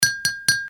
Play, download and share 0MoGa0 D02 (Spoon) original sound button!!!!
spoon.mp3